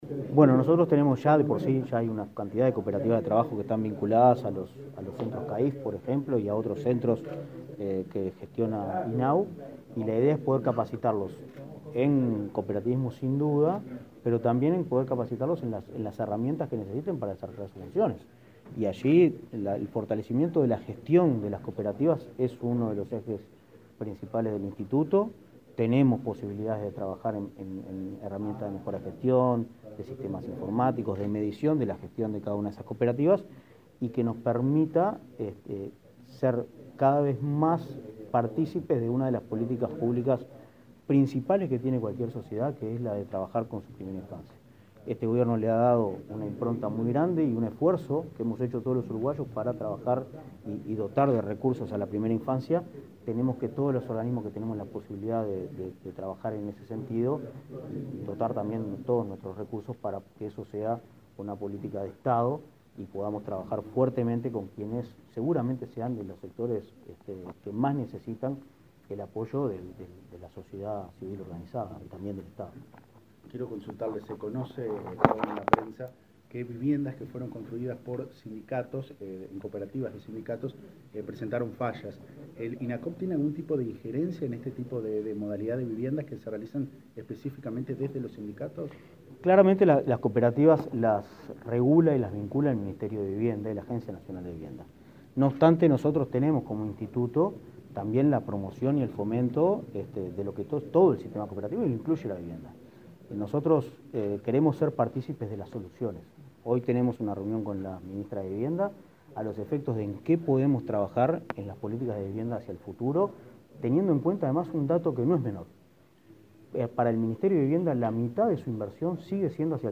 Declaraciones a la prensa del titular del Inacoop
Este martes 14, los presidentes del Instituto del Niño y Adolescente del Uruguay (INAU), Pablo Abdala, y el Instituto Nacional del Cooperativismo (Inacoop), Martín Fernández, firmaron un convenio, cuya finalidad es capacitar a nuevas organizaciones para facilitar la gestión de los centros de infancia y adolescencia. Luego, Fernández dialogó con la prensa.